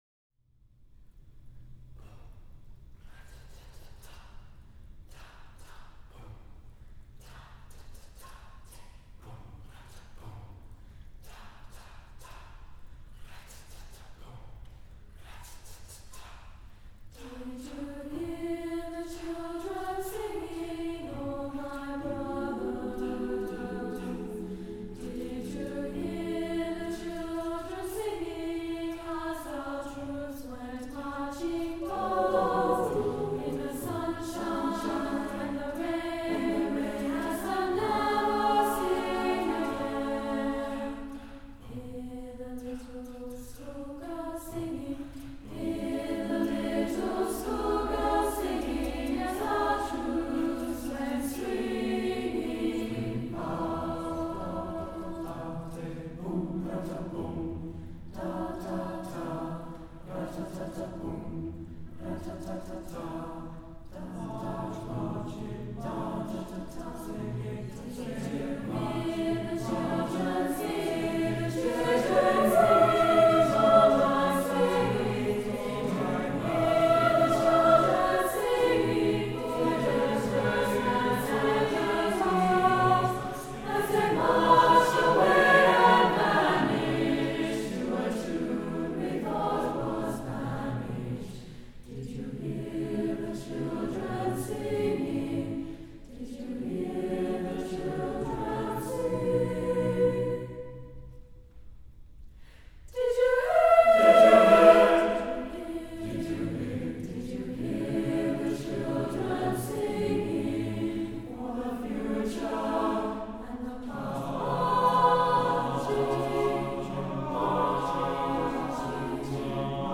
SATB a cappella choir